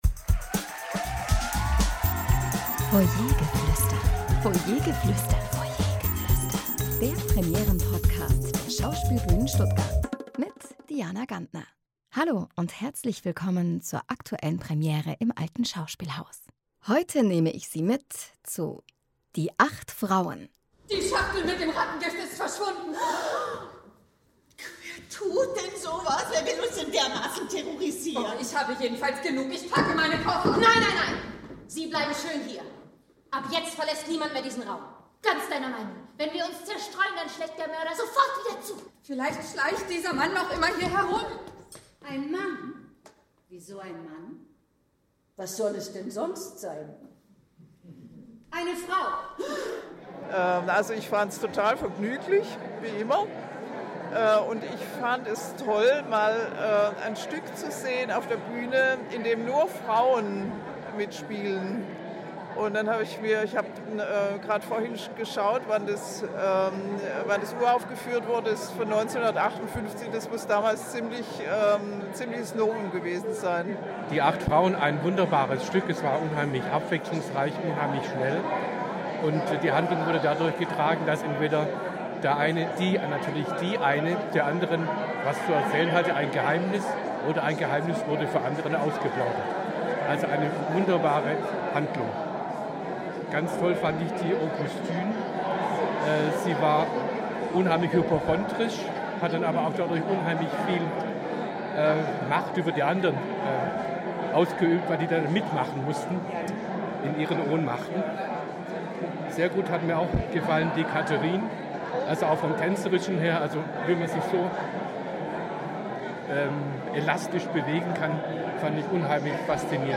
Publikumsstimmen zur Premiere von “Die acht Frauen”